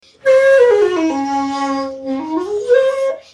Shakuhachi 51